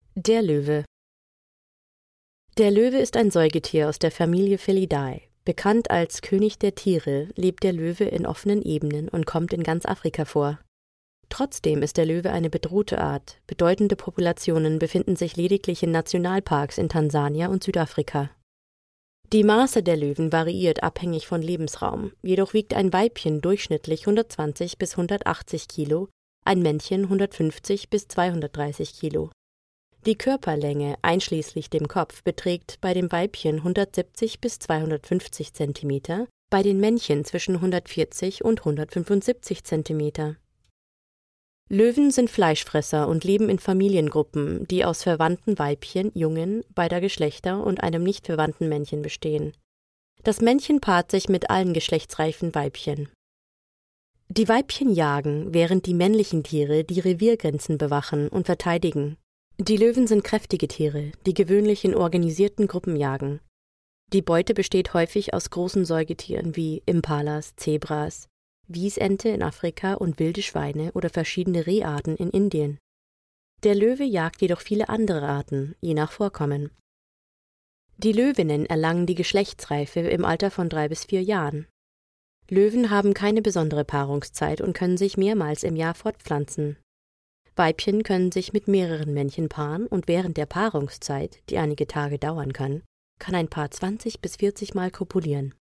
Voice Overs
DE JE EL 01 eLearning/Training Female German